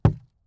ES_Walk Wood Creaks 16.wav